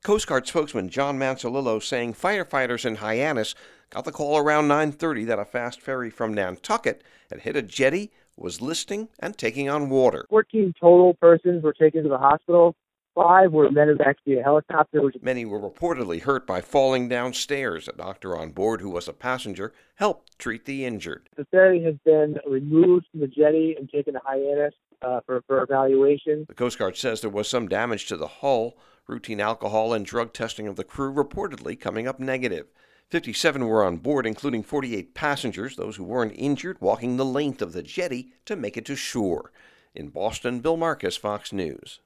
(BOSTON) JUN 17 -A FAST FERRY ACCIDENT IN HYANNIS, MASSACHUSETTS FRIDAY NIGHT DURING HEAVY STORMS HOSPITALIZED MORE THAN A DOZEN PEOPLE. FOX NEWS RADIO’S